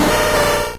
Cri de Mackogneur dans Pokémon Or et Argent.